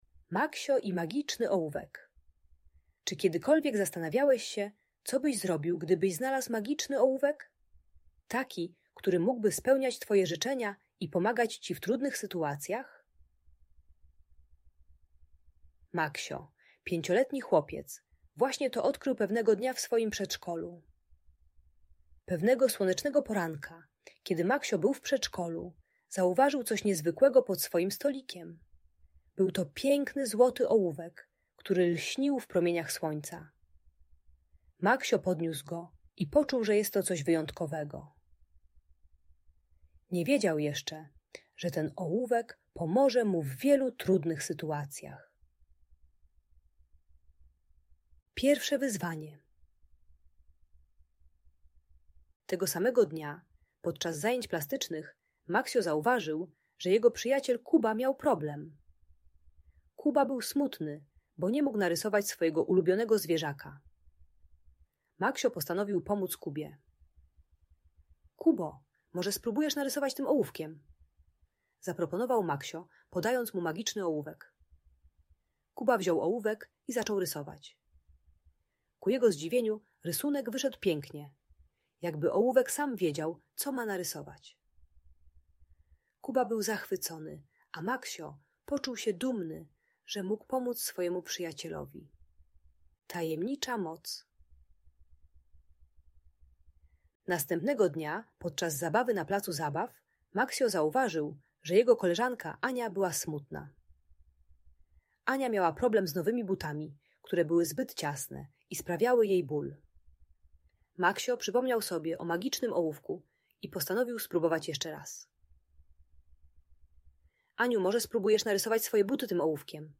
Maksio i Magiczny Ołówek - Fascynująca Story - Audiobajka dla dzieci